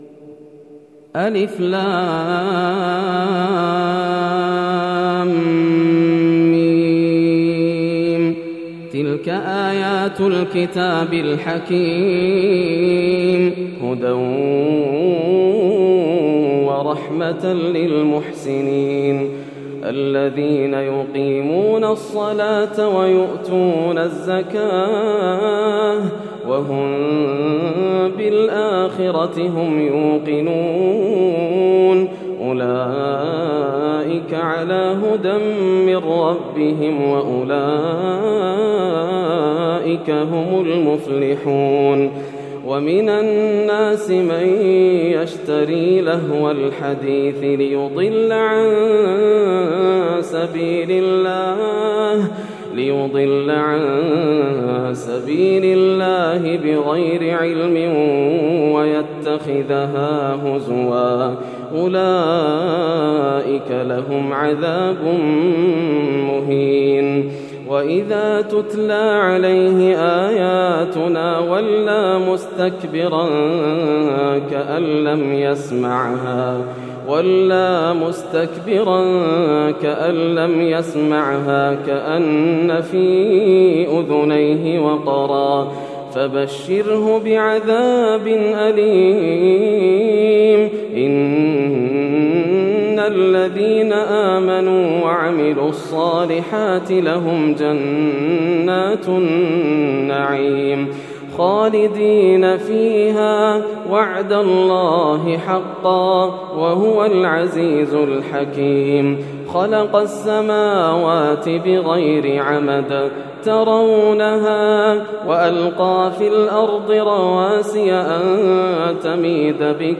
سورة لقمان > السور المكتملة > رمضان 1431هـ > التراويح - تلاوات ياسر الدوسري